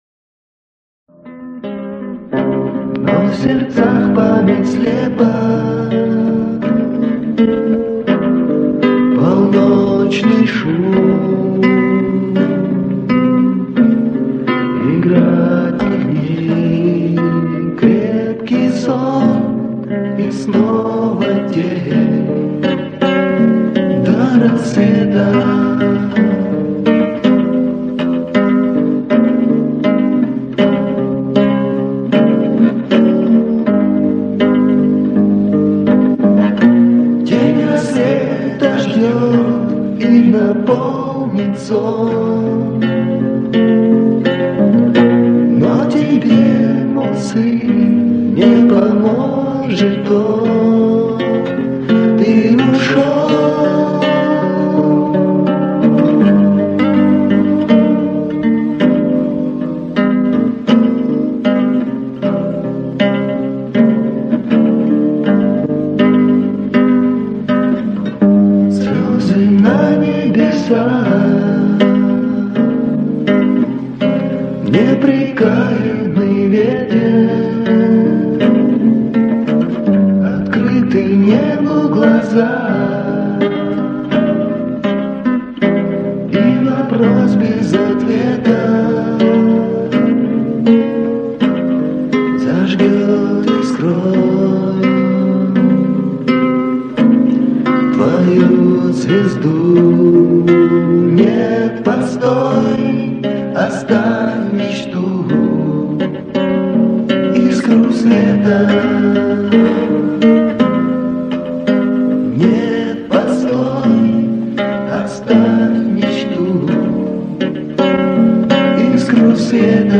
Гитарная демка (1995)
Начало записи к сожалению "зажёванно" основательно )))